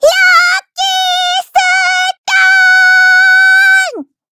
voices / heroes / kr
Taily-Vox_Skill3_kr_b.wav